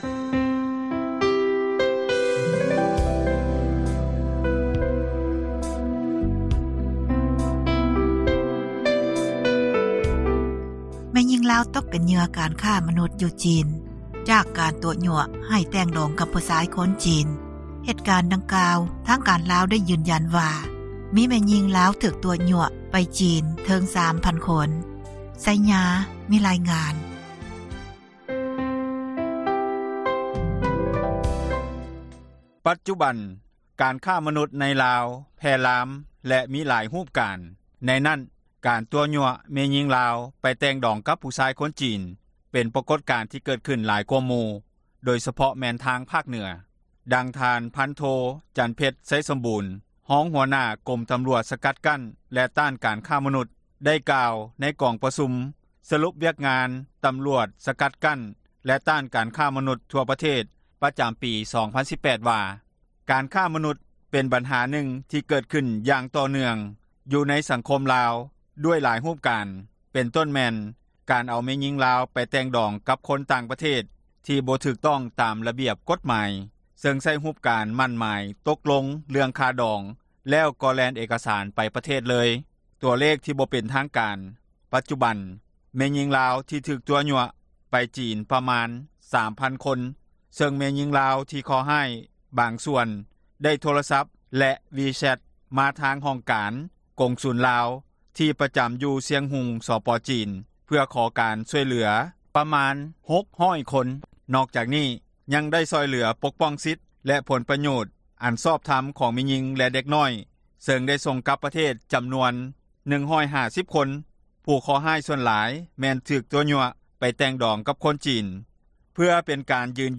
ເຈົ້າໜ້າທີ່ ກ່ຽວຂ້ອງໄດ້ໃຫ້ສັມພາດ ຕໍ່ RFA ໃນວັນທີ 18 ຕຸລາ ນີ້ວ່າ: ເຊີນທ່ານຮັບຟັງ ການສຳພາດ...